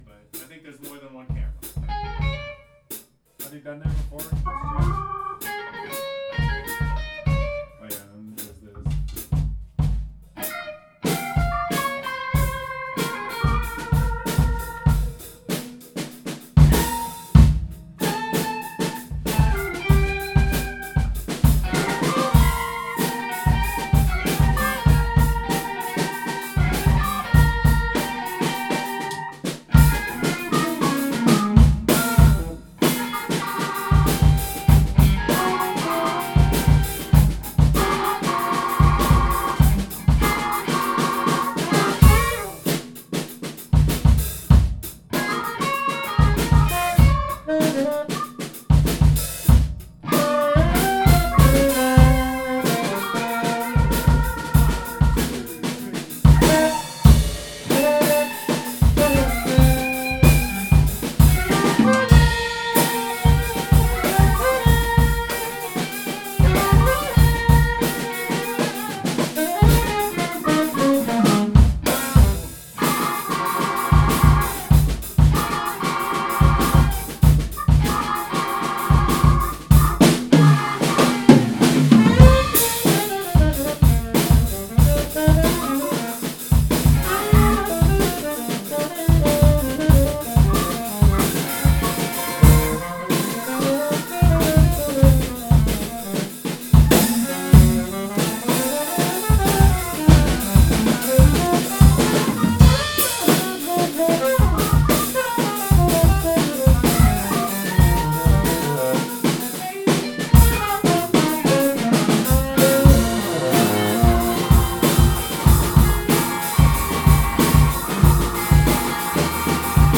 Bbm